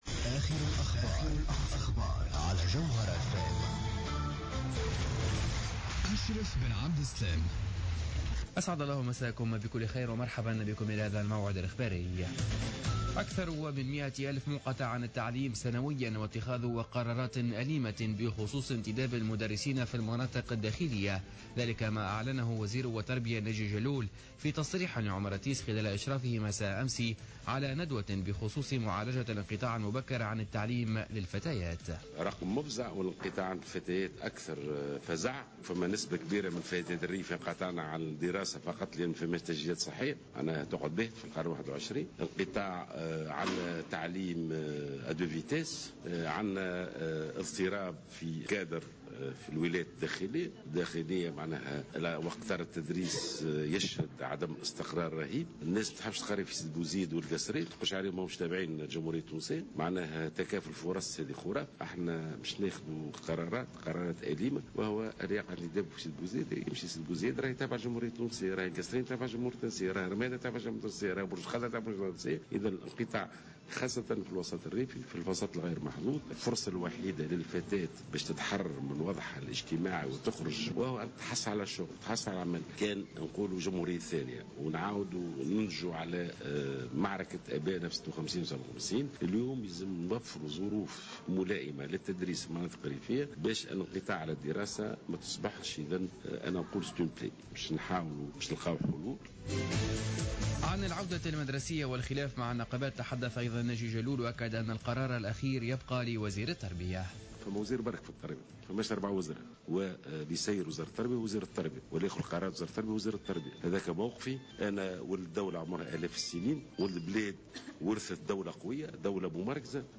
نشرة أخبار منتصف الليل ليوم السبت 15 أوت 2015